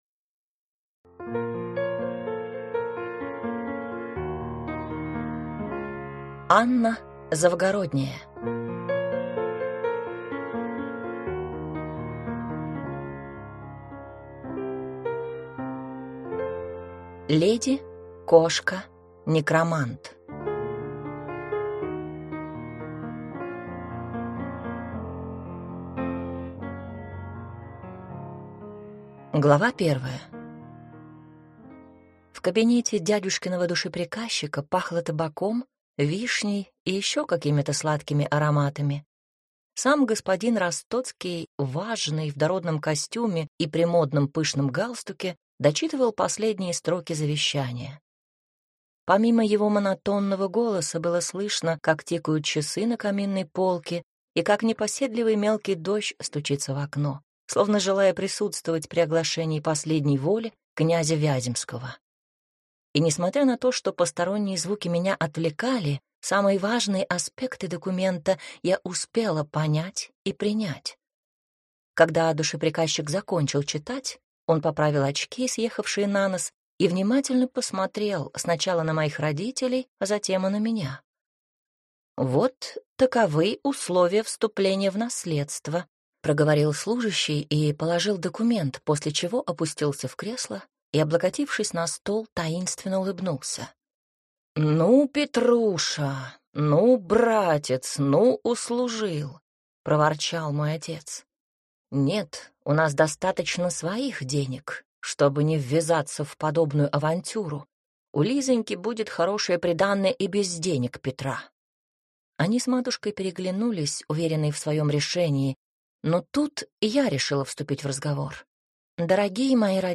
Аудиокнига Леди. Кошка. Некромант | Библиотека аудиокниг
Прослушать и бесплатно скачать фрагмент аудиокниги